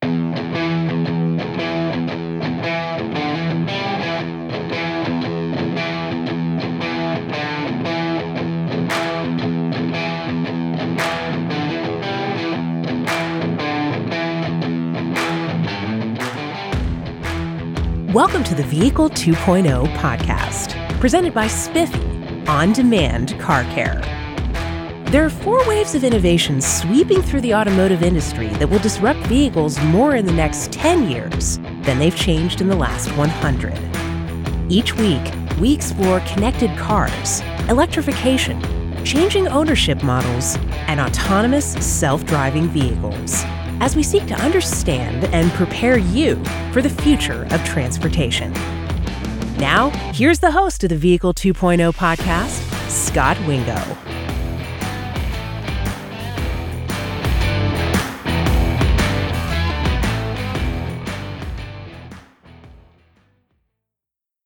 Podcast Intro/Outro